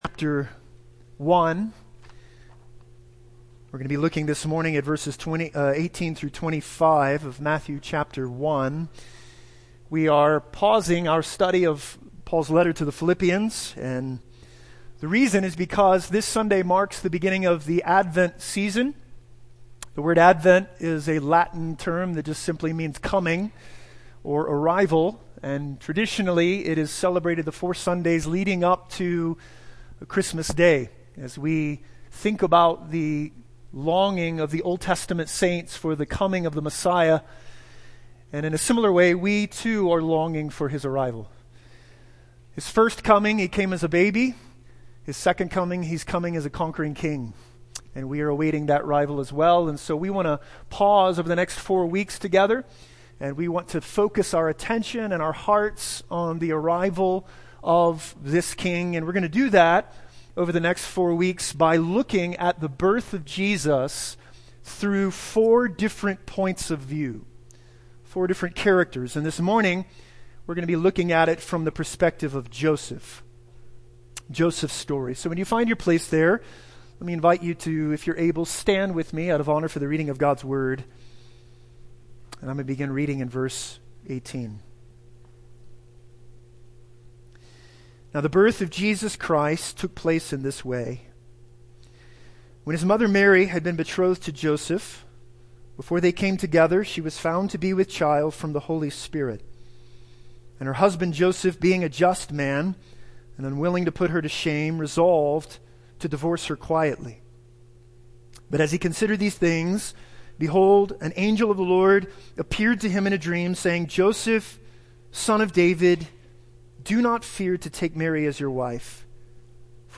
A message from the series "The Birth of a King."